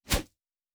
pgs/Assets/Audio/Fantasy Interface Sounds/Whoosh 03.wav at master
Whoosh 03.wav